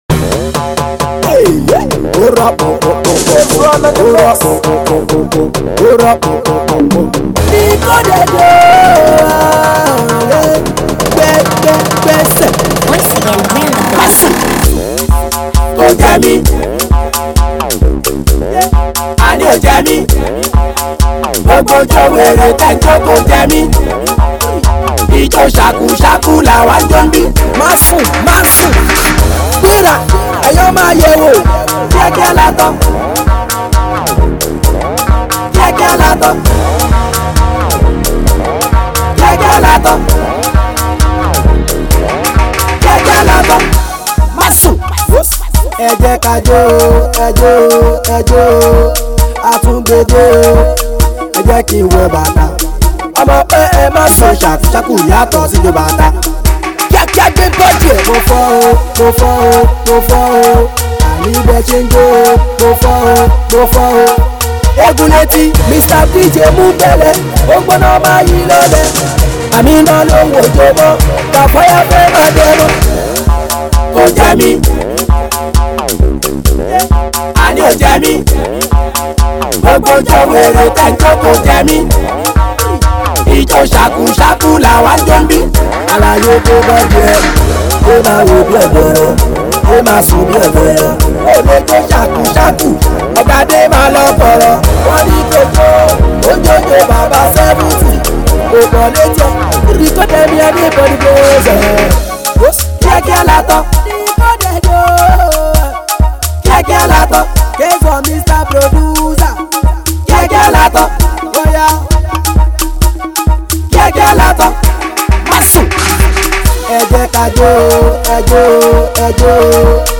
dance hall tune